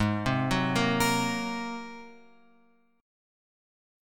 G# 9th